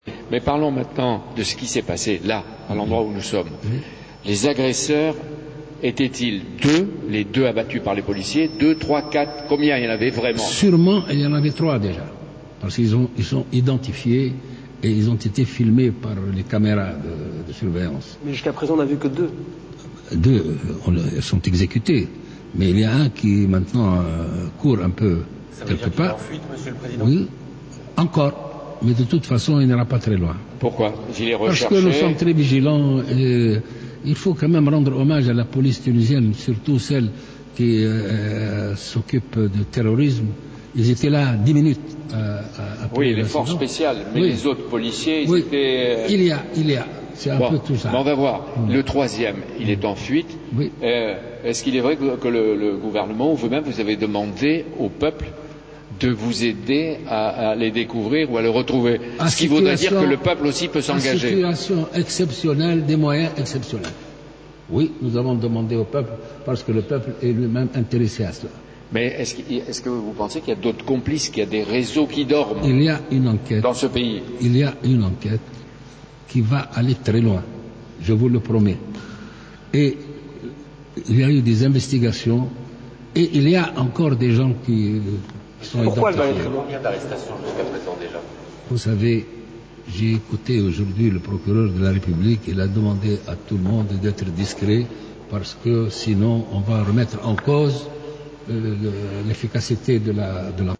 صرح رئيس الجمهورية الباجي قائد السبسي في حوار مع قناتي Europe1 و iTélé الفرنسيتين من متحف باردو صباح اليوم الأحد، أن عدد منفذي الهجوم الإرهابي بمتحف باردو كان 3 أشخاص.